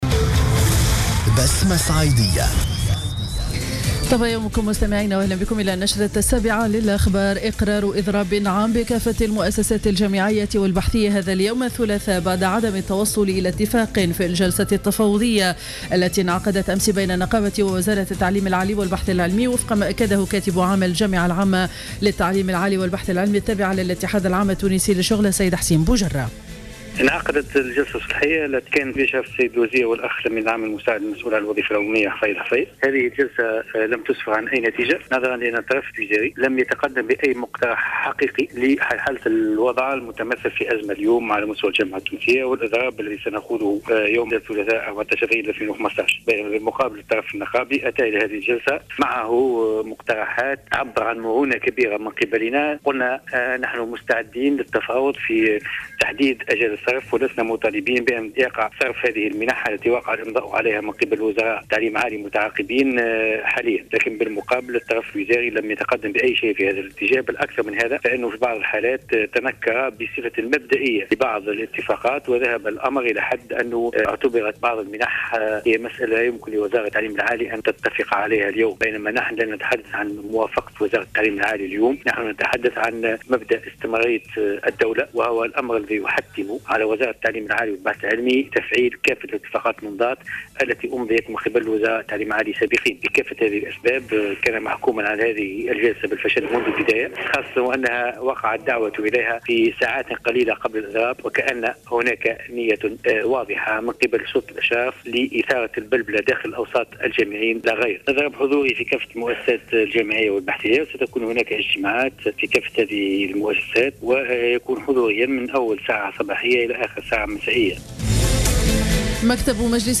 نشرة أخبار السابعة صباحا ليوم الثلاثاء 14 أفريل 2015